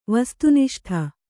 ♪ vastu niṣṭ`ha